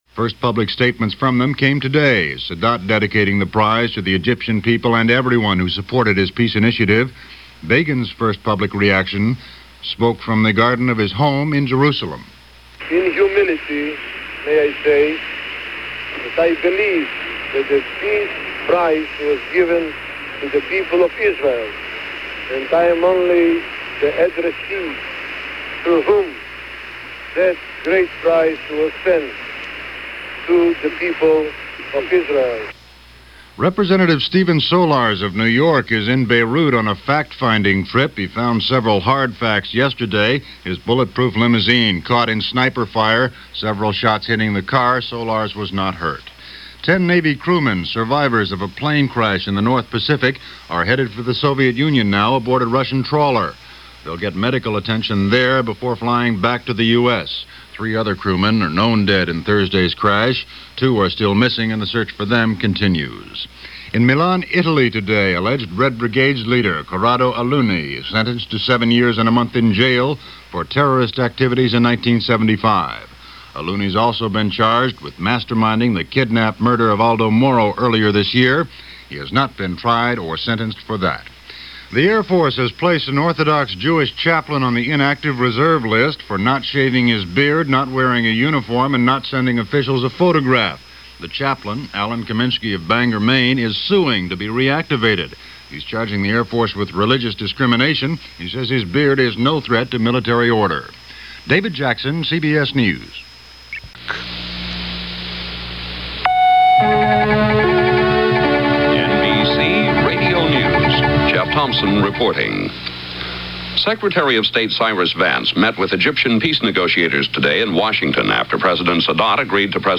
And that’s a small slice of what went on in the world, this October 28, 1978 as reported by CBS Hourly News and NBC Hourly News.